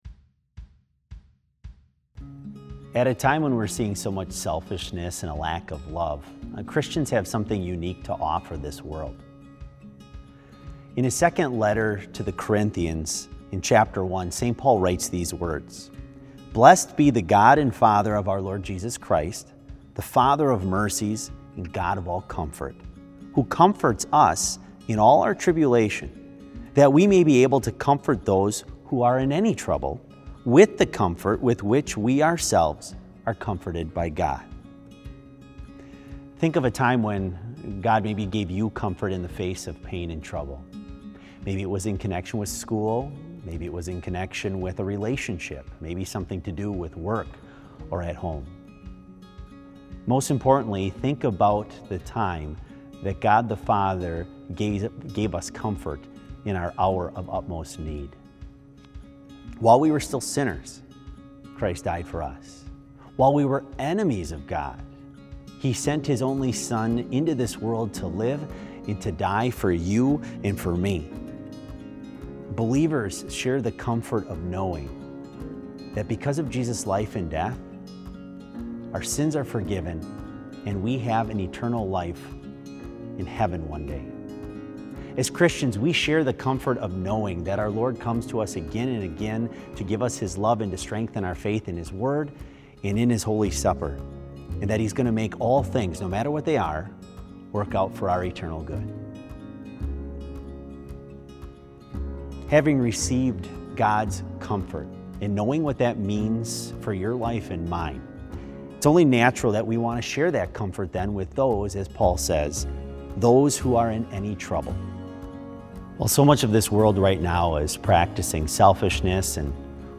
Complete Service
This Special Service was held in Trinity Chapel at Bethany Lutheran College on Tuesday, March 24, 2020, at 10 a.m. Page and hymn numbers are from the Evangelical Lutheran Hymnary.